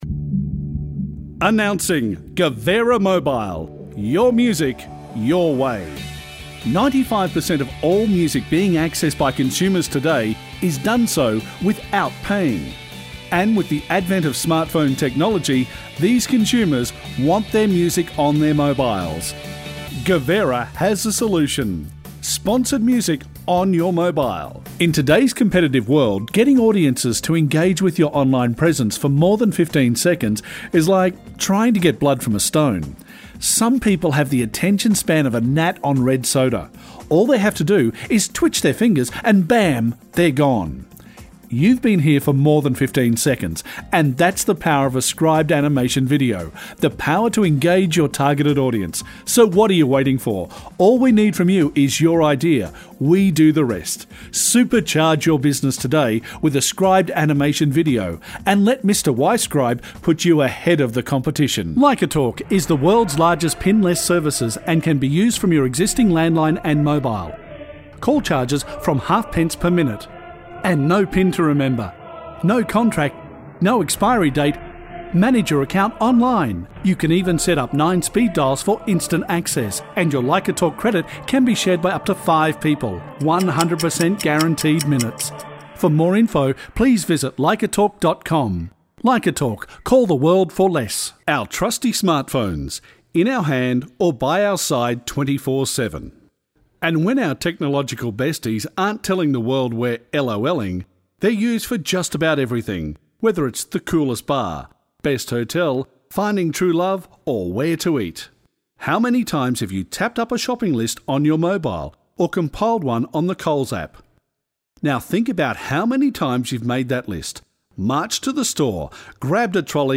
Voice Overs